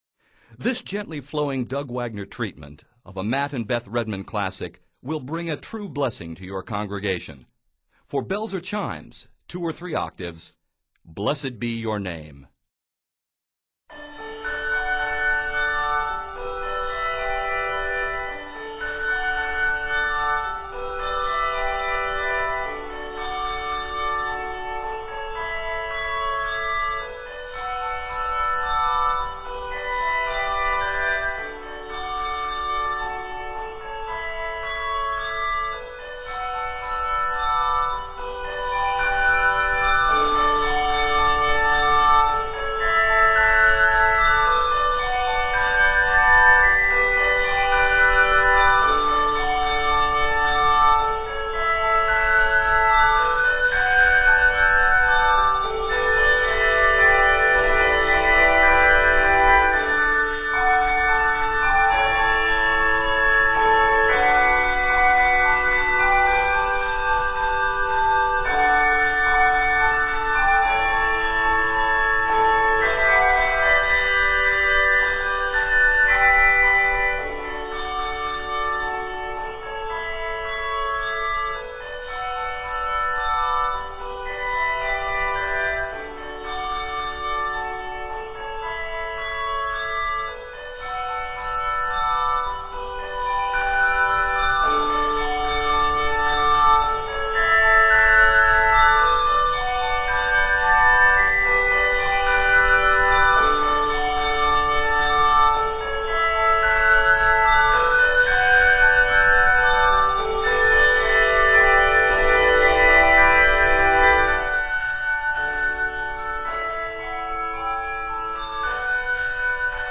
Set in D Major, this arrangement is 69 measures.